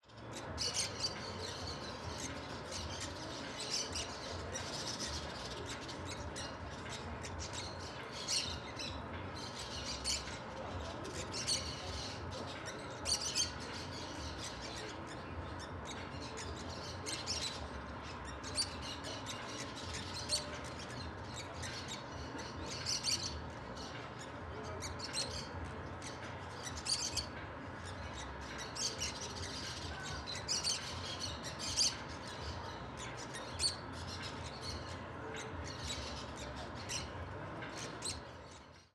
Varios Periquitos Verdes Vocalizando em Bosque Urbano
Periquito
Surround 5.1
CSC-07-082-GV - Varios Periquitos Verdes Vocalizando em Bosque Urbano.wav